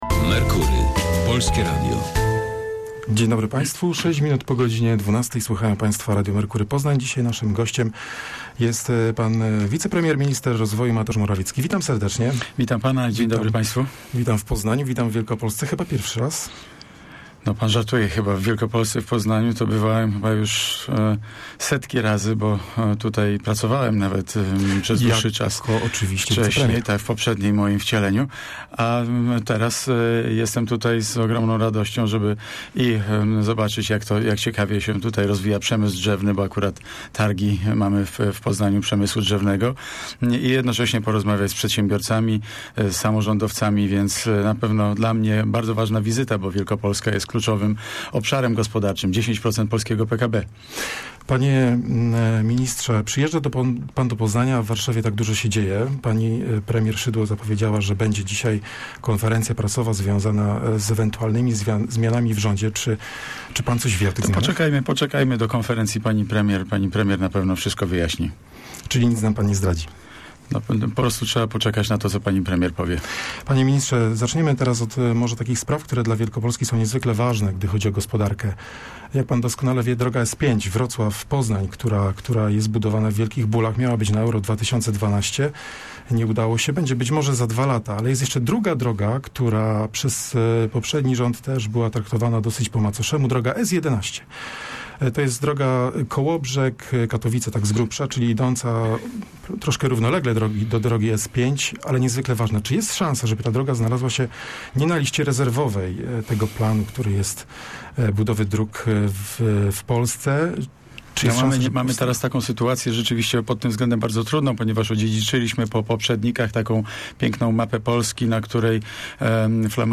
rozmawiał z ministrem Mateuszem Morawieckim
bk1e95xmp30dwuh_rozmowa_morawiecki.mp3